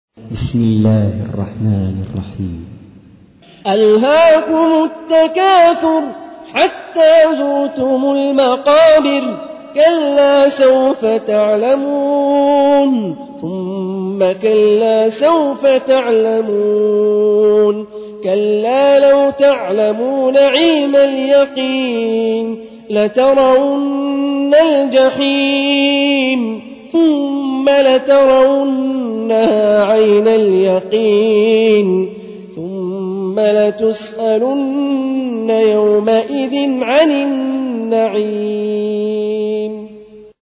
تحميل : 102. سورة التكاثر / القارئ هاني الرفاعي / القرآن الكريم / موقع يا حسين